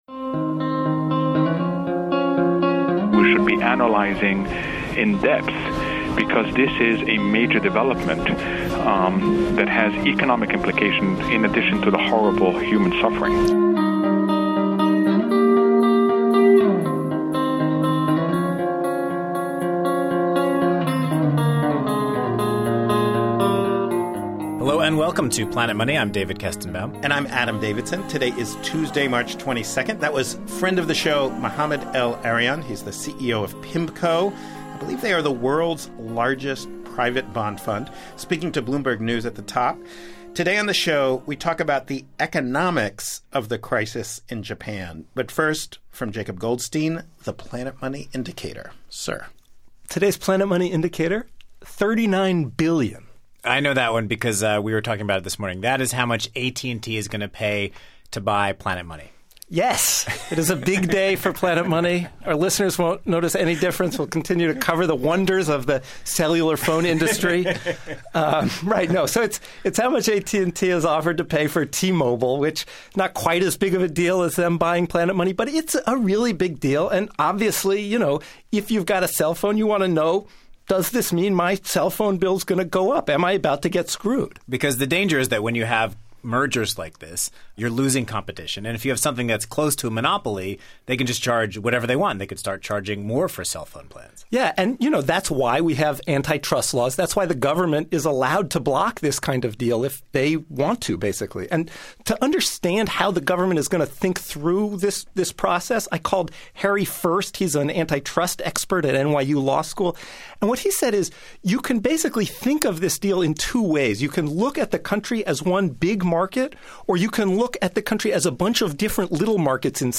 There are big, short-term economic problems. On today's show, a man who works at a major Japanese truck and bus manufacturer describes widespread factory closures and disruptions in the supply of parts. But in the long-term, for all of the suffering, Japan's economy is likely to bounce back from the disaster.